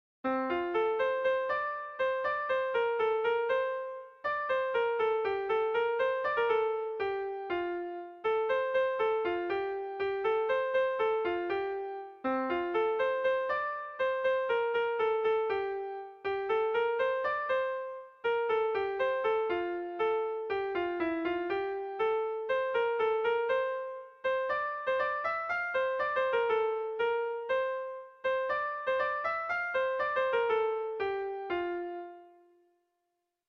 Bertso melodies - View details   To know more about this section
Kontakizunezkoa